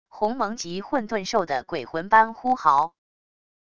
鸿蒙级混沌兽的鬼魂般呼号wav音频